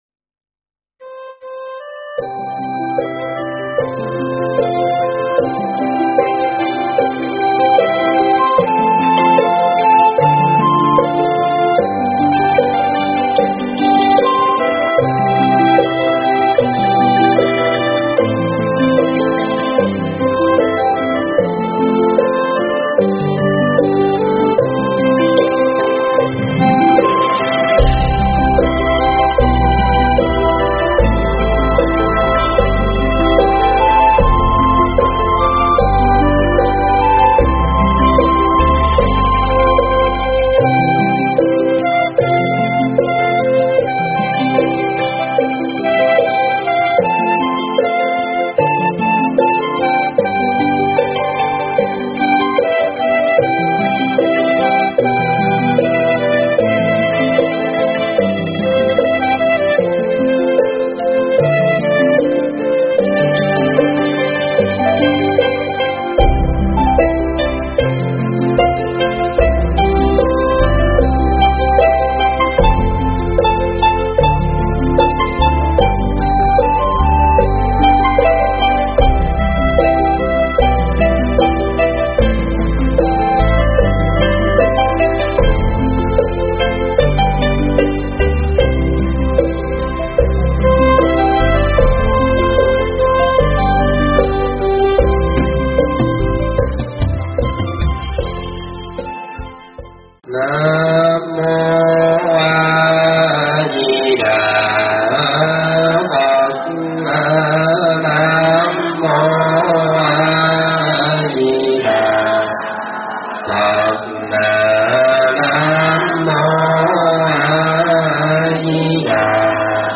thuyết pháp Biết Tự Tại
giảng tại Tv. Giác Ngộ Cape May, NJ